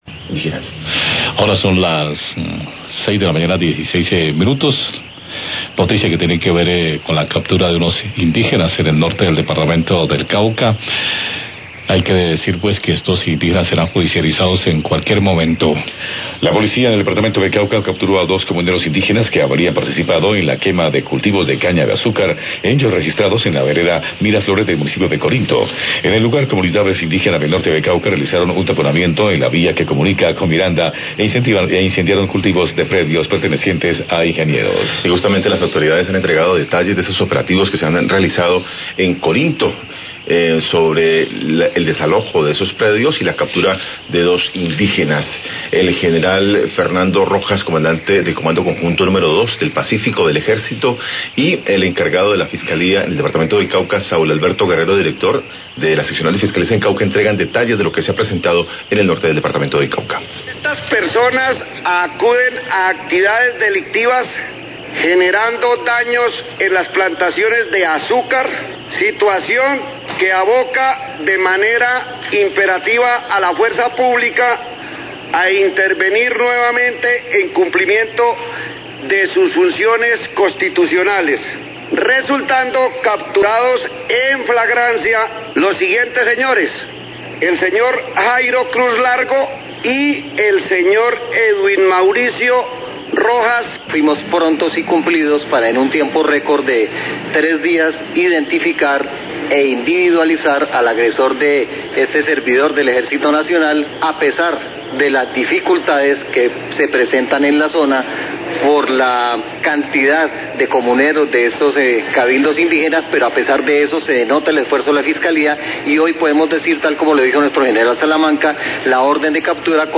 Radio
La Policía del Cauca capturó a dos comuneros indígenas que habrían participado en la quema de cultivos de caña de azúcar en la vereda Miraflores, Corinto. Declaraciones del General Fernando Rojas, Comandante del Comando Conjunto No. 2 del Pacífico del Ejército y Saul Guerrero, Director de la Seccional Fiscalía Cauca.